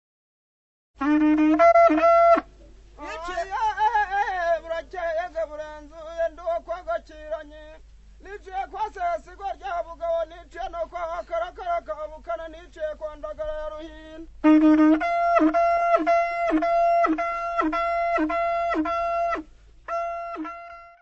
Amahigi, chant de chasse.